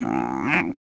sounds / monsters / cat